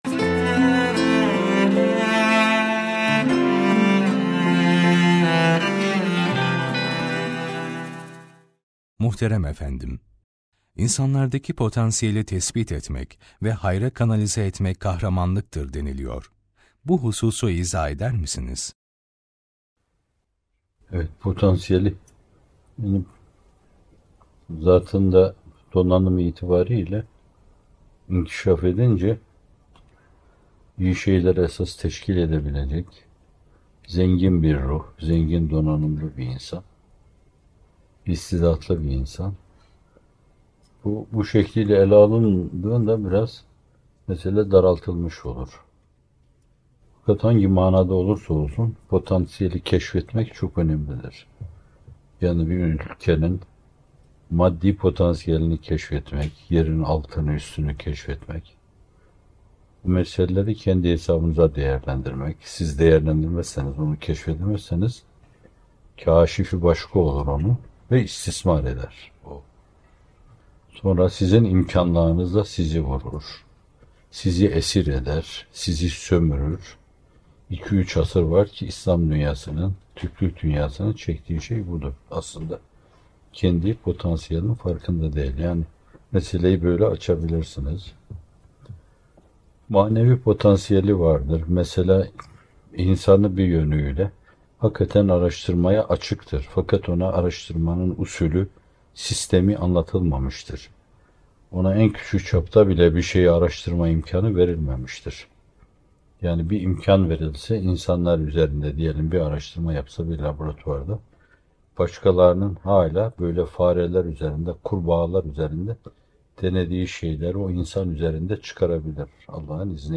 İnsanlardaki Potansiyelin Hayra Kanalize Edilmesi - Fethullah Gülen Hocaefendi'nin Sohbetleri